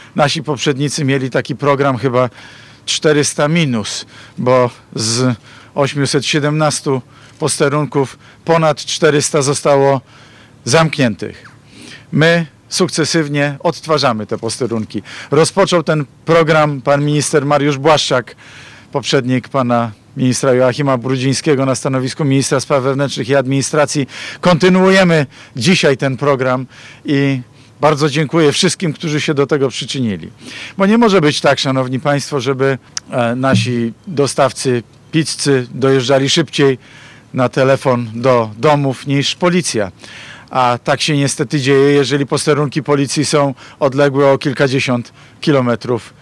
Mateusz Morawiecki, premier Rzeczypospolitej Polskiej wziął w czwartek (28.02.19) udział w otwarciu, reaktywowanego po 19-tu latach, posterunku policji w Dubeninkach.